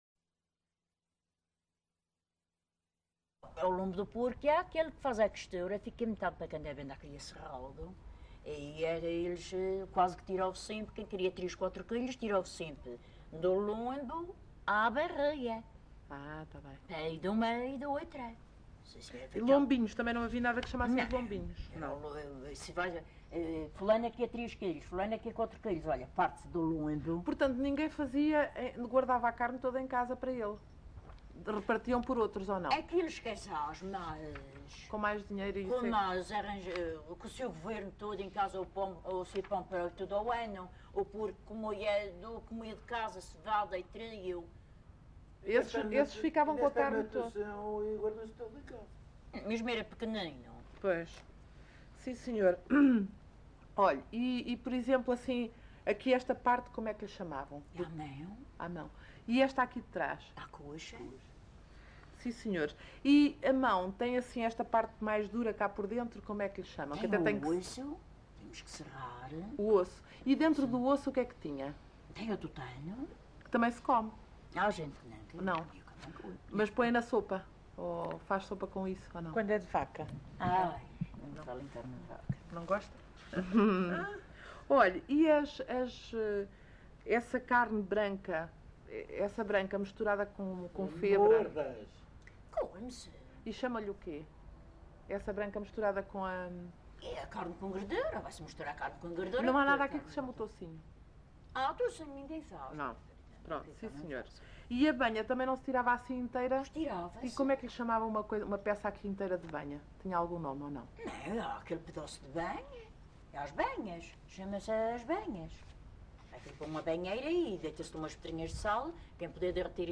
LocalidadeCamacha (Porto Santo, Funchal)